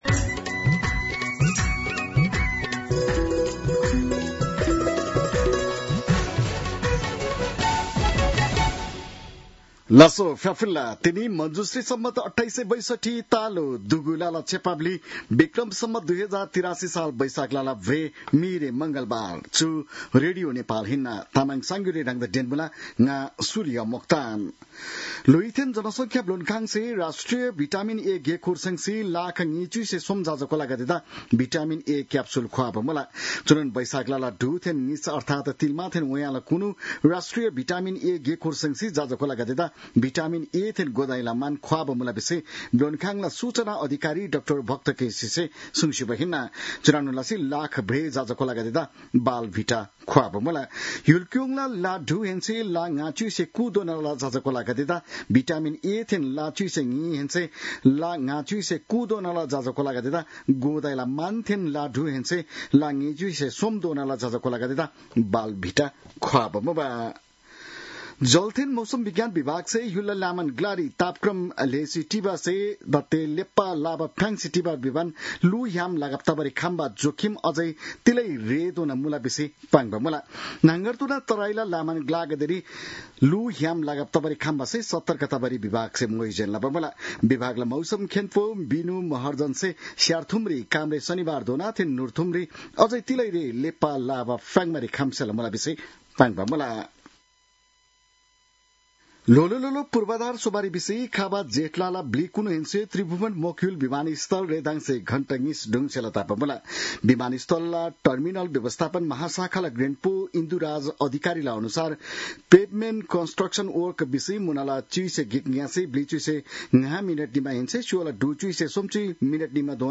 तामाङ भाषाको समाचार : ८ वैशाख , २०८३